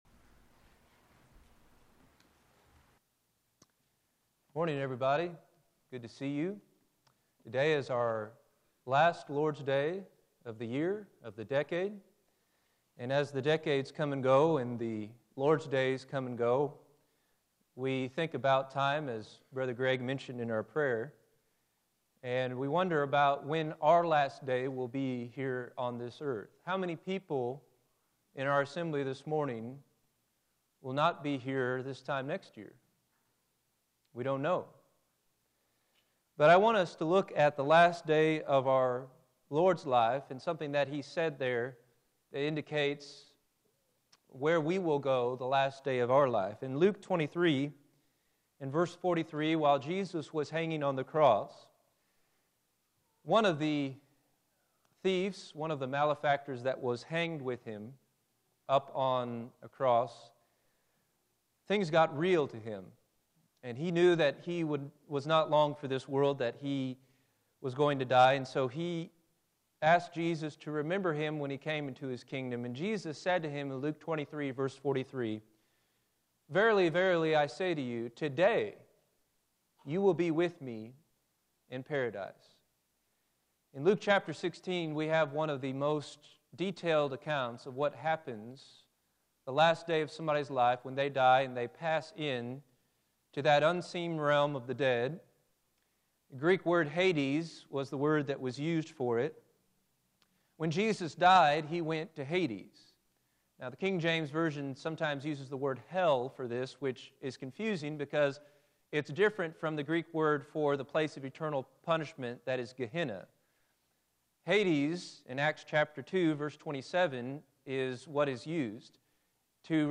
Bible Study – Exodus – Fall 2019